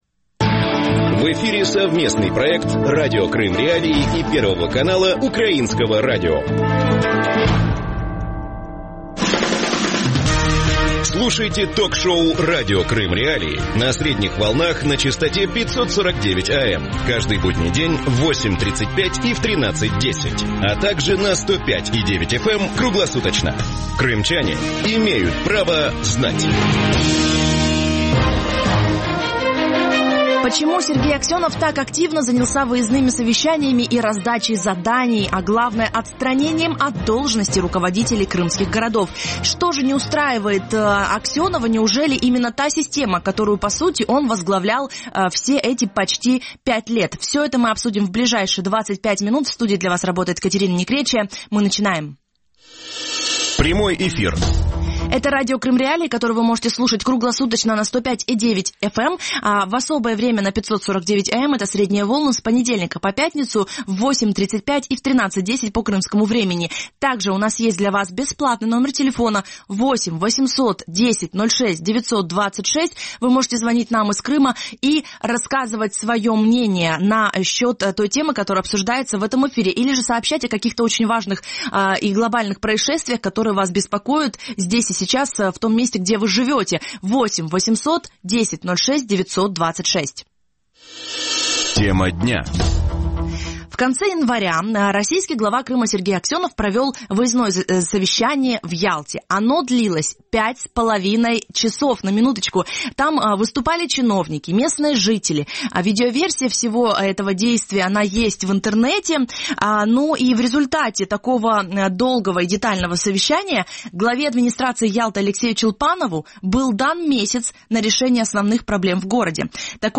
Гости эфира